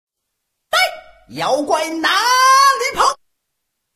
孙悟空喊妖怪哪里跑音效_人物音效音效配乐_免费素材下载_提案神器